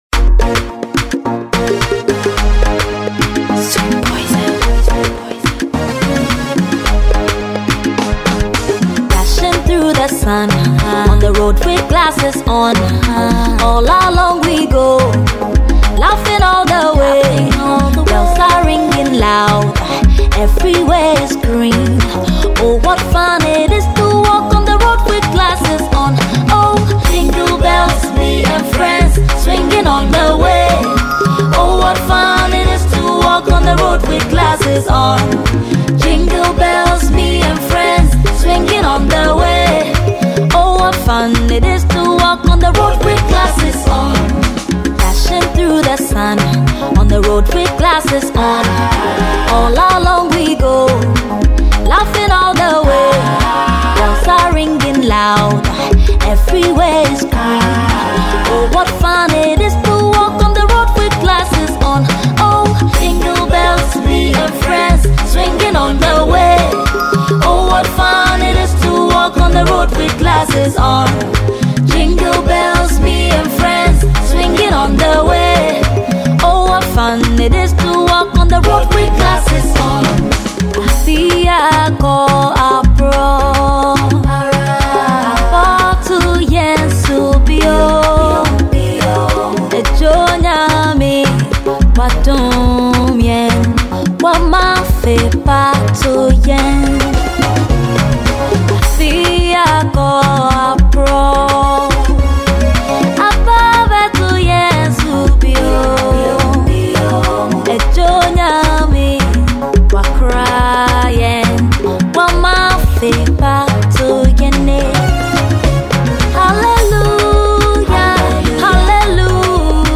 a Ghanaian female artist
Enjoy this amazing studio track.